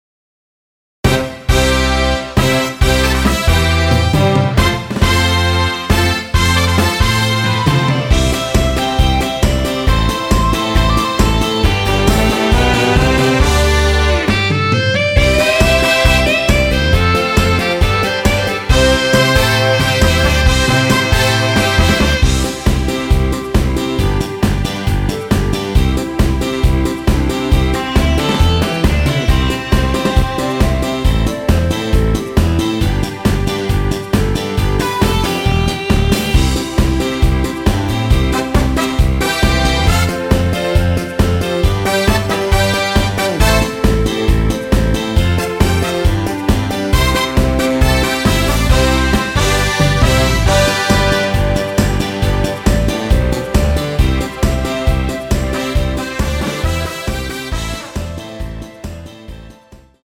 원키에서(+2)올린 멜로디 포함된 MR입니다.
멜로디 MR이라고 합니다.
앞부분30초, 뒷부분30초씩 편집해서 올려 드리고 있습니다.